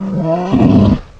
boar_panic_2.ogg